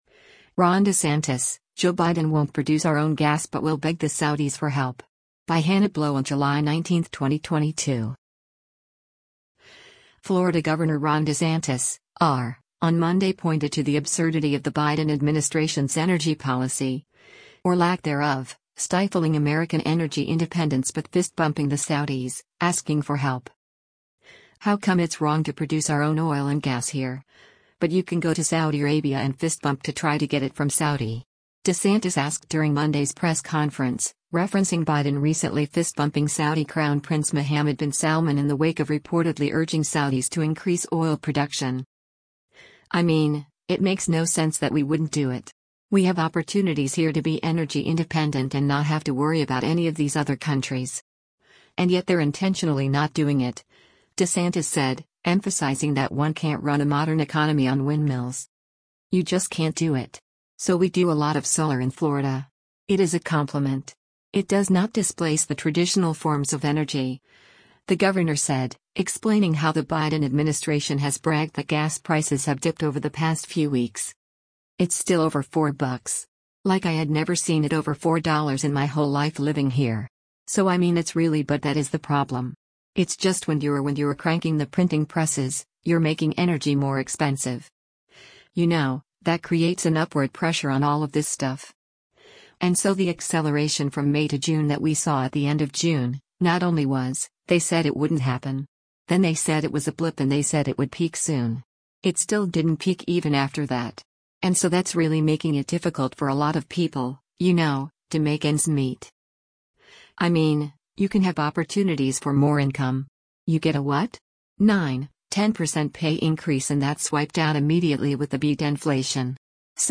“How come it’s wrong to produce our own oil and gas here, but you can go to Saudi Arabia and fist bump to try to get it from Saudi?” DeSantis asked during Monday’s press conference, referencing Biden recently fist-bumping Saudi Crown Prince Mohammed bin Salman in the wake of reportedly urging Saudis to increase oil production: